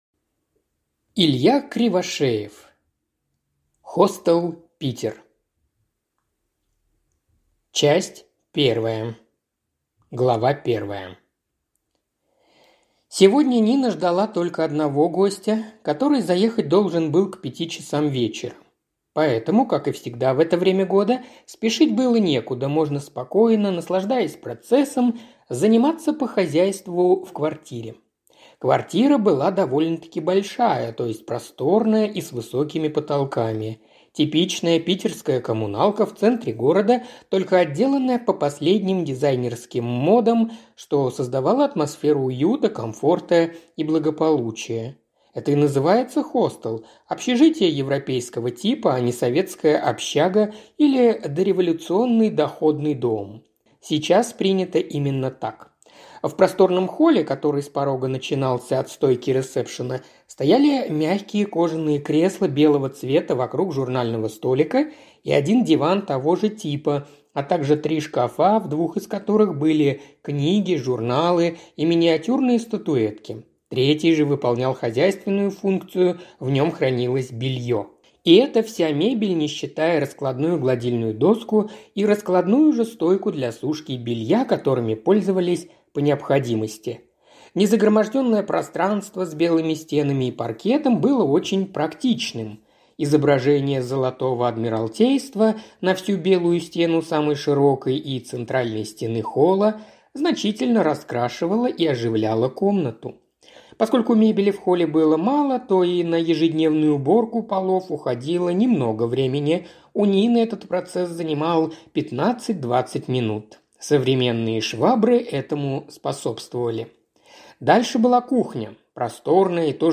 Аудиокнига Хостел «Питер» | Библиотека аудиокниг